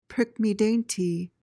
PRONUNCIATION:
(prik-mee-DAYN-tee)